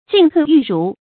進退裕如 注音： ㄐㄧㄣˋ ㄊㄨㄟˋ ㄧㄩˋ ㄖㄨˊ 讀音讀法： 意思解釋： 謂前進和后退均從容不費力。